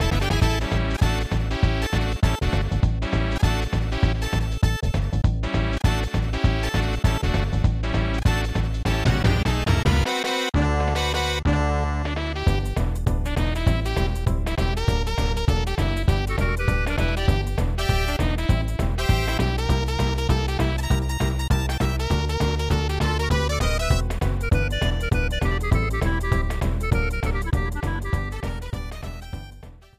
Bonus stage intro theme